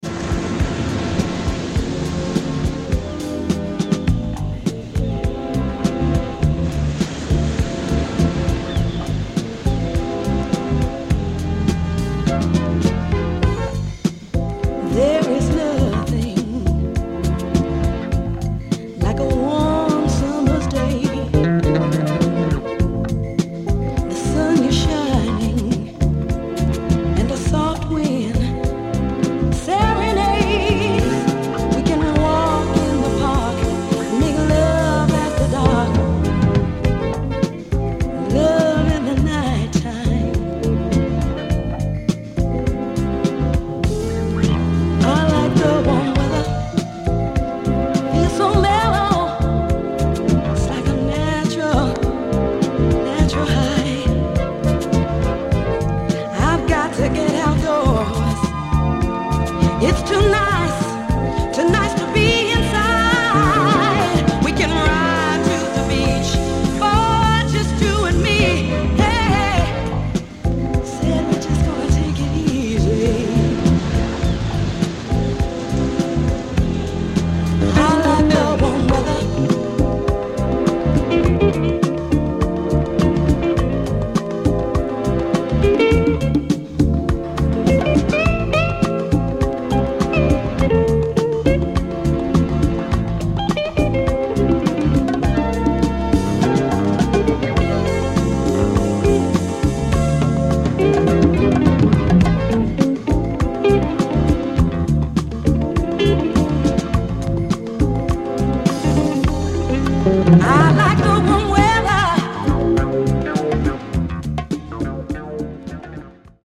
Classic boogie-Fusion from 1981 w